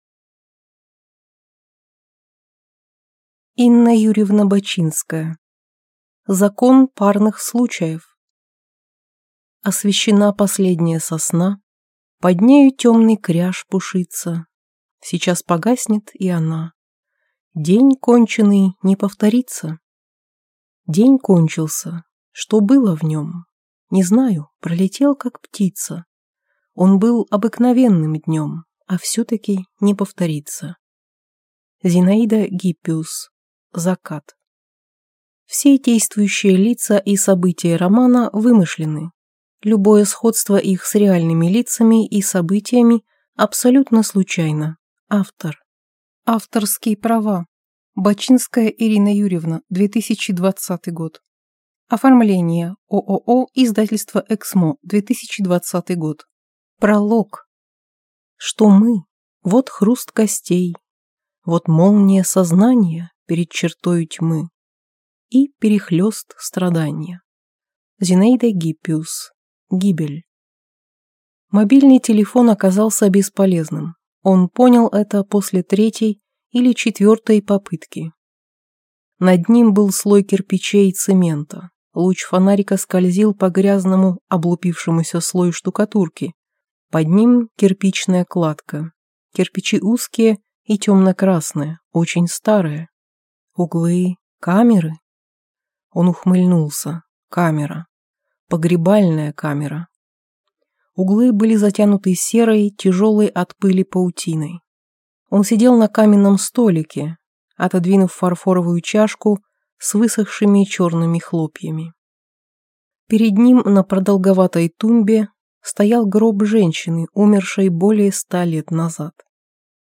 Аудиокнига Закон парных случаев | Библиотека аудиокниг